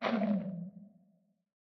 sculk_clicking_stop4.ogg